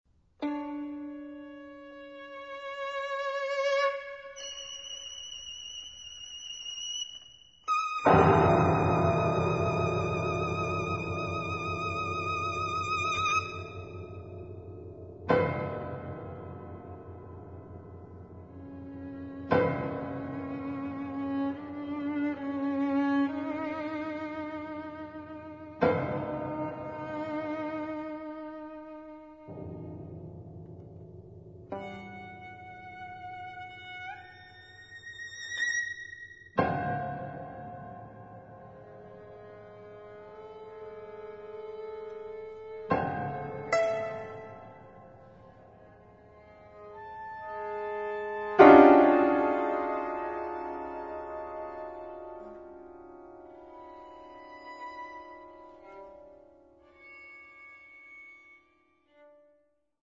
Piano
Violin